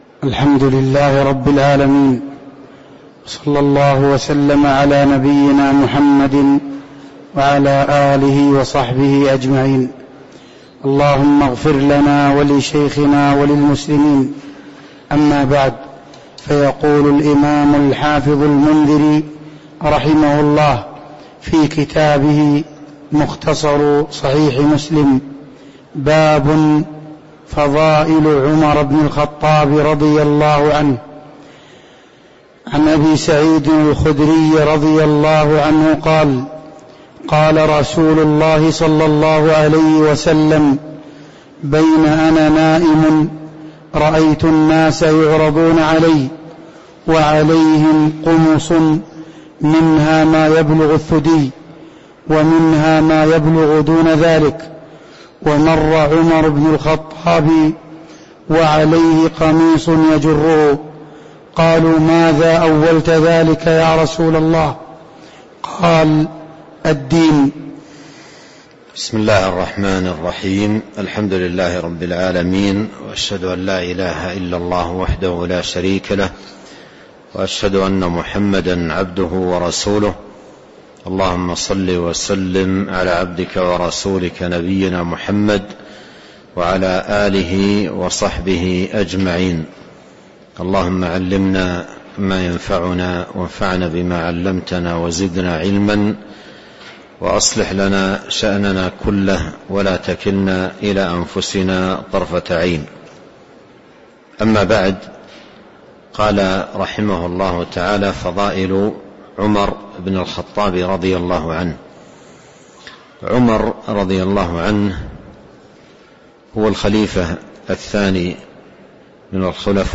تاريخ النشر ٧ رمضان ١٤٤٣ هـ المكان: المسجد النبوي الشيخ